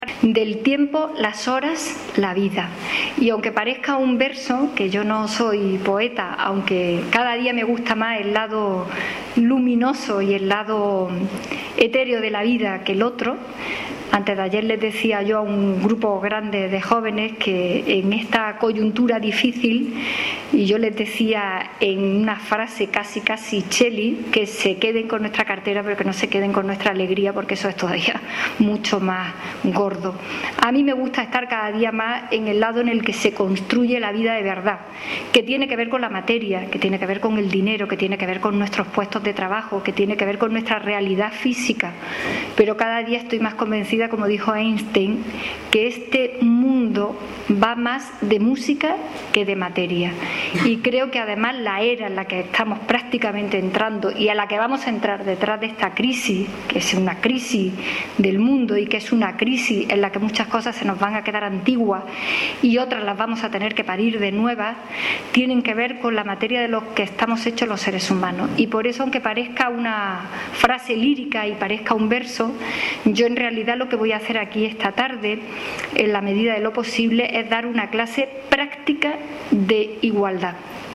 Conferencia de Carmen Calvo
La Sala Antequerana de la Biblioteca Supramunicipal de San Zoilo se ha llenado por completo de público.
Cortes de voz